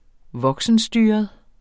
Udtale [ ˈvʌgsənˌsdyːʌð ]